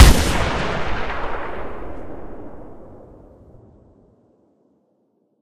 kraber_far.ogg